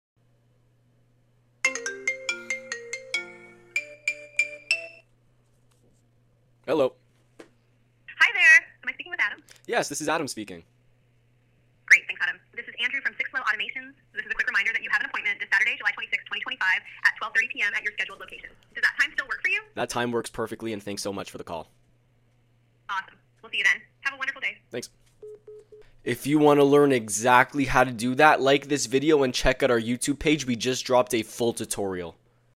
Appointment reminder calls AI voice